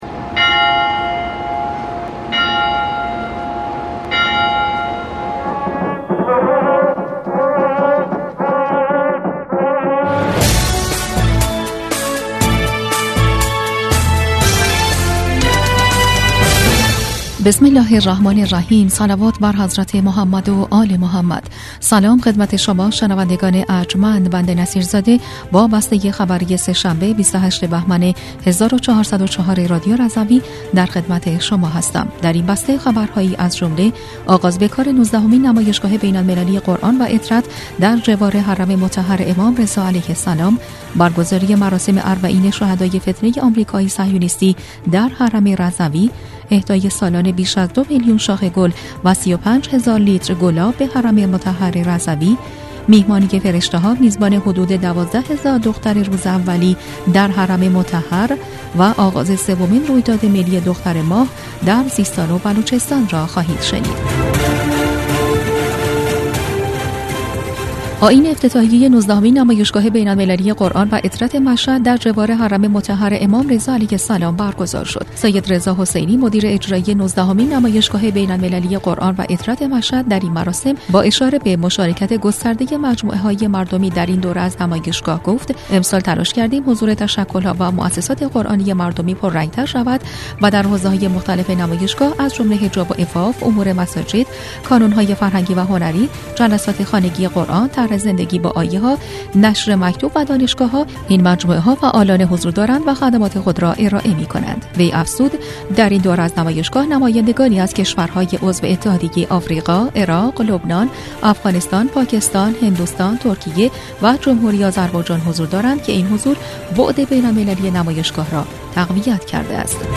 بسته خبری ۲۸ بهمن ۱۴۰۴ رادیو رضوی؛